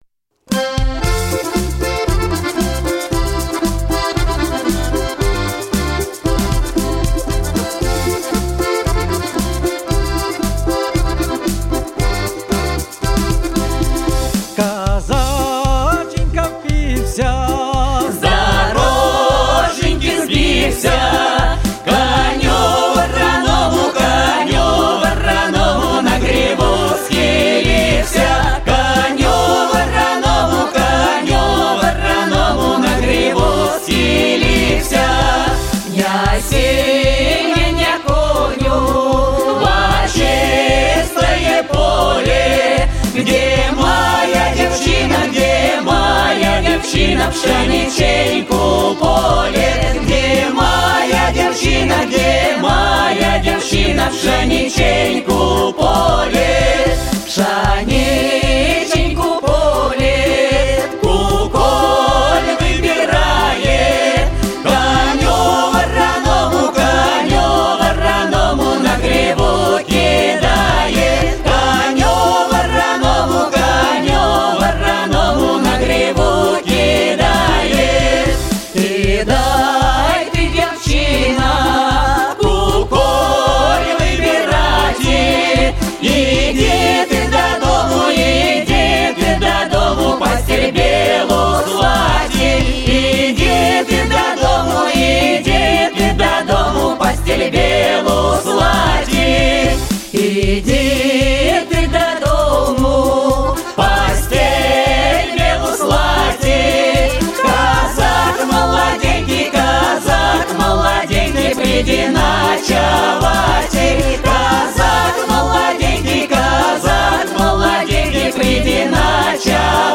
Коллектив почти год трудился в студии звукозаписи